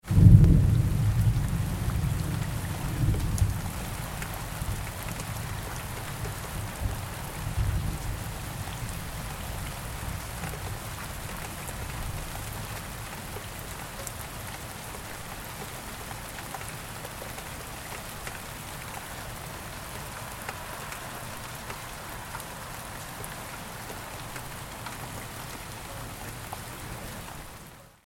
جلوه های صوتی
دانلود صدای طوفان 11 از ساعد نیوز با لینک مستقیم و کیفیت بالا
برچسب: دانلود آهنگ های افکت صوتی طبیعت و محیط دانلود آلبوم صدای طوفان از افکت صوتی طبیعت و محیط